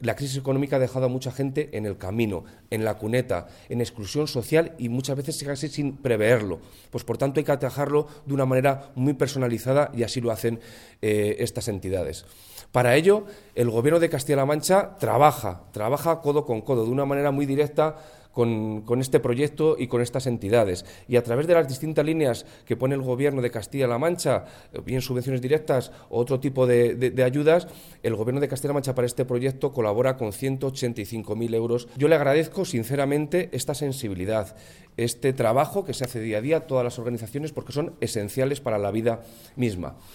El delegado de la Junta en Guadalajara, Alberto Rojo, habla del apoyo del Gobierno regional al 'proyecto integral' de ACCEM y Cáritas.